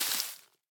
Minecraft Version Minecraft Version 1.21.5 Latest Release | Latest Snapshot 1.21.5 / assets / minecraft / sounds / block / cherry_leaves / step2.ogg Compare With Compare With Latest Release | Latest Snapshot
step2.ogg